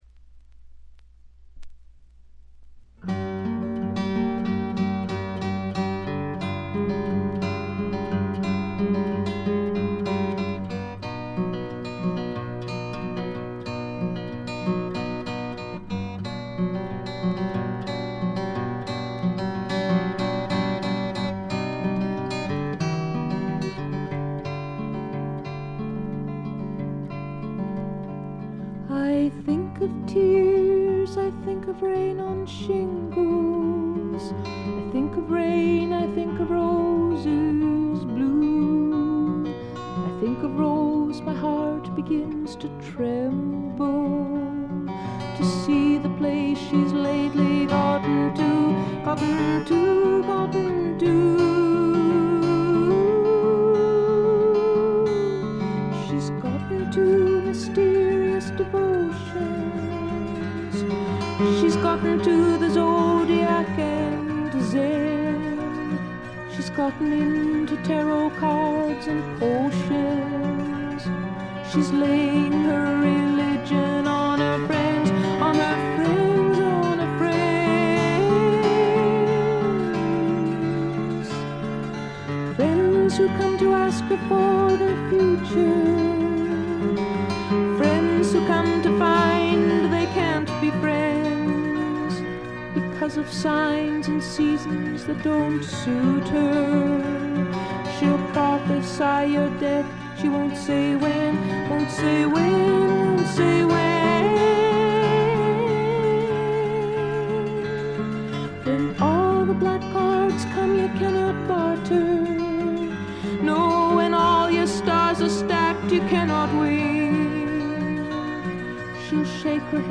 これ以外は微細なバックグラウンドノイズ程度。
透明感のあるみずみずしさが初期の最大の魅力です。
女性フォーク／シンガーソングライター・ファンなら避けては通れない基本盤でもあります。
試聴曲は現品からの取り込み音源です。